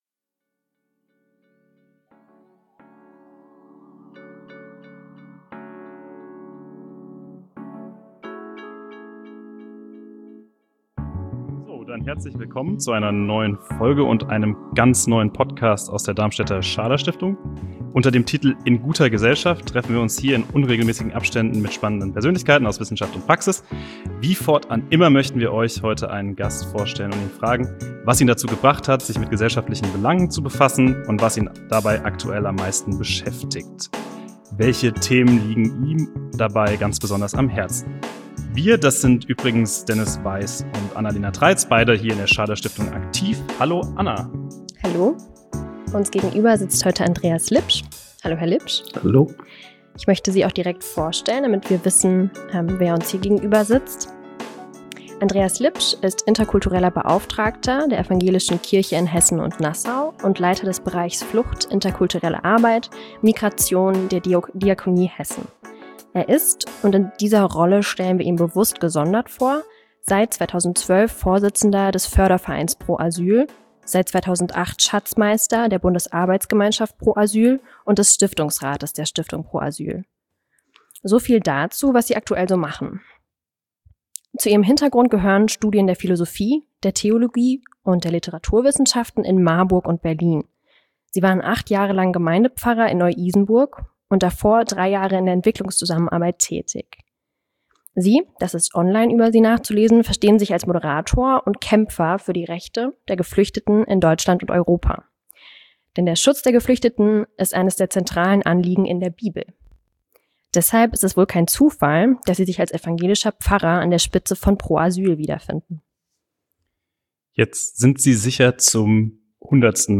In unserem Gespräch erzählt er uns, warum ihm weder die Bücherstube noch der politische Aktivismus allein genügen und wo wissenschaftliche Erkenntnisse in den schnelllebigen Diskurs um Flüchtlings- und Migrationspolitik einfließen können.